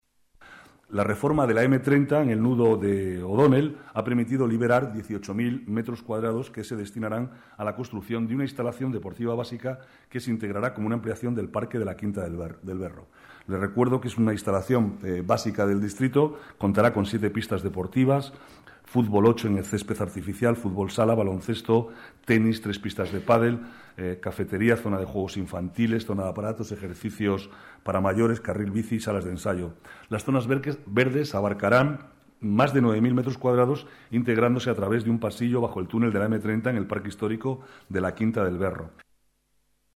Nueva ventana:Declaraciones del vicealcalde, Manuel Cobo, sobre la nueva instalación deportiva Torrespaña